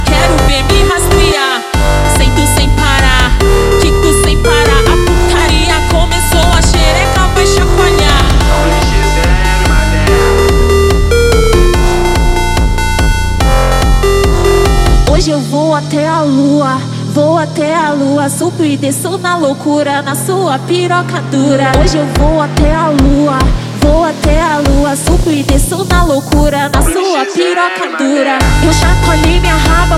Жанр: Фанк
# Baile Funk